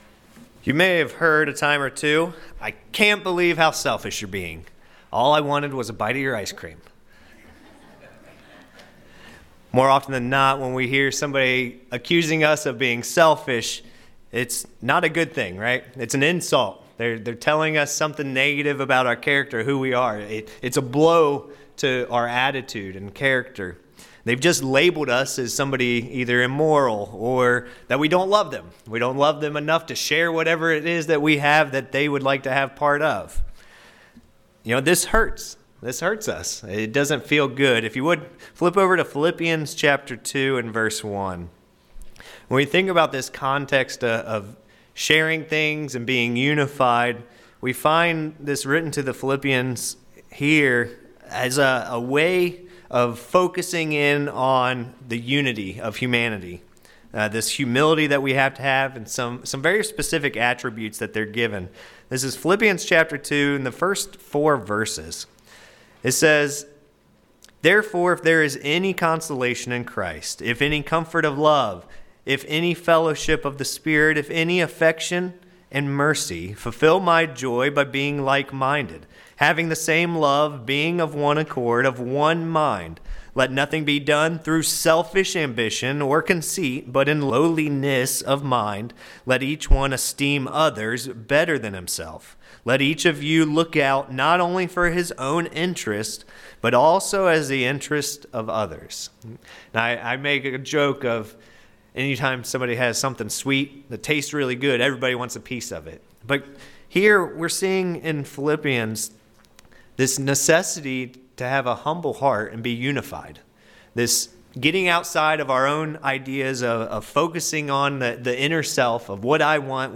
This sermon discusses how we must move beyond just focusing on ourselves. If we don't, we are not showing love to others.